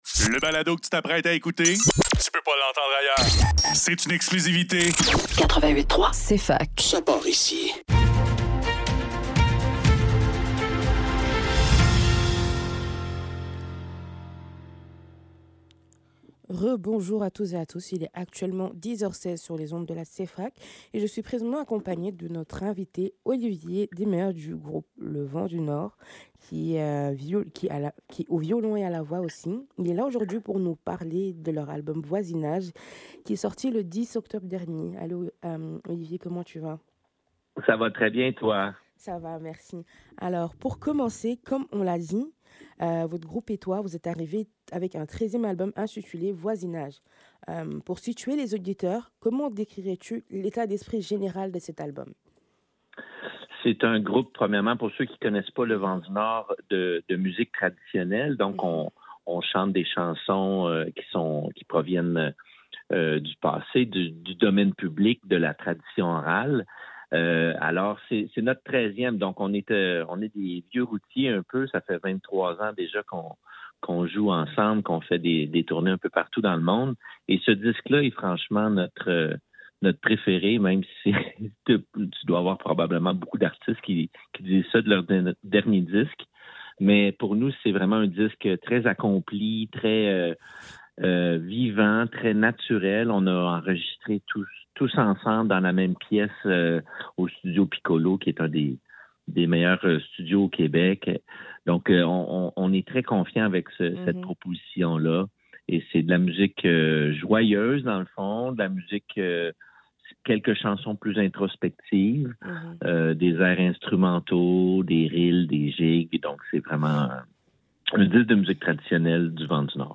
Le neuf - Entrevue avec Le Vent du Nord - 25 novembre 2025